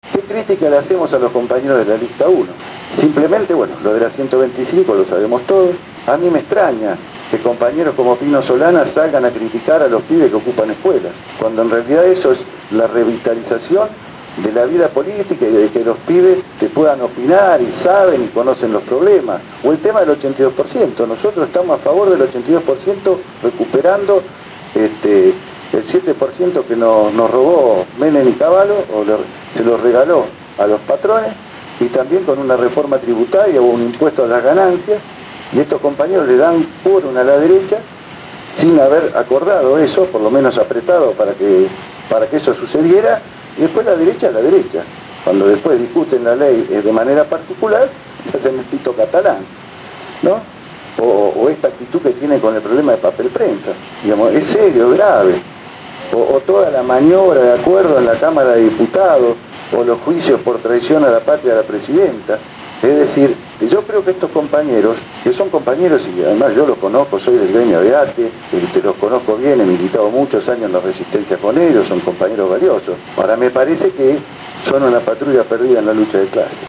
El último lunes se realizó en las instalaciones de Radio Gráfica la charla-debate: «Los Trabajadores y el Proyecto Nacional«, todo ésto de cara a las elecciones internas de la Central de Trabajadores de la Argentina el próximo 23 de Septiembre.